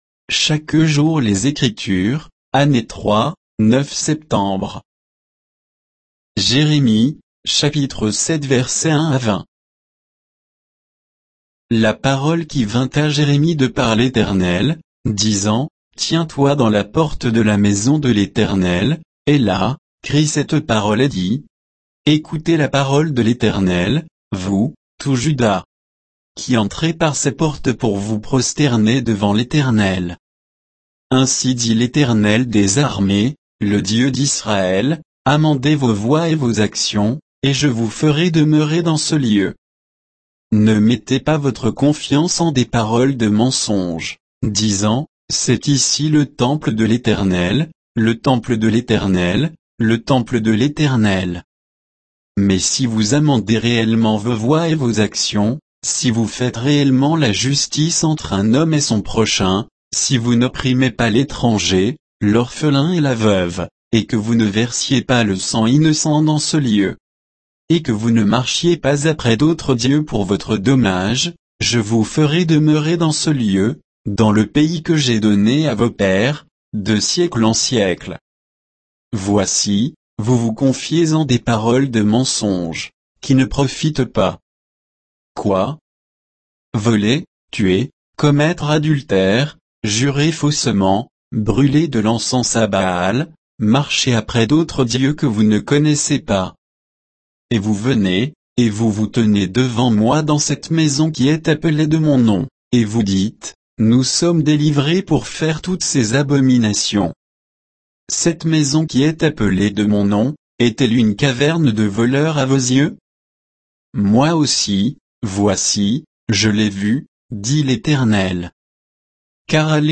Méditation quoditienne de Chaque jour les Écritures sur Jérémie 7, 1 à 20